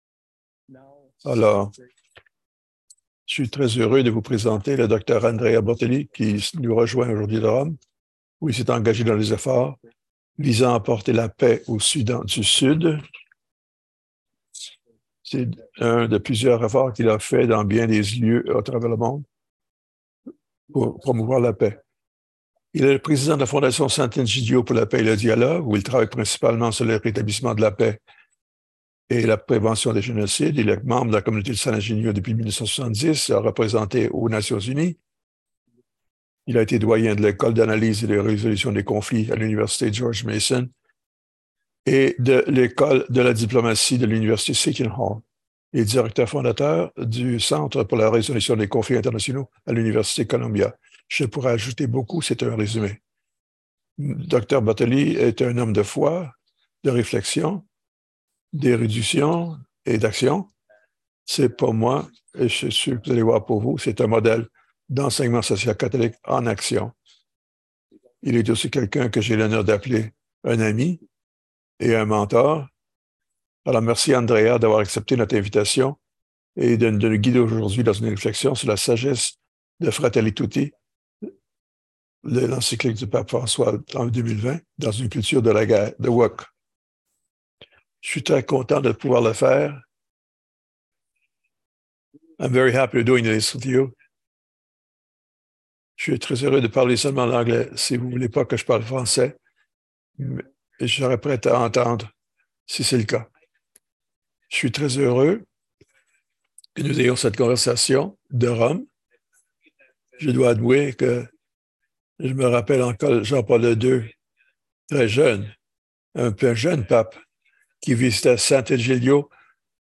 Vidéo en anglais - cliquer ici pour écouter l'interprétation simultanée en français.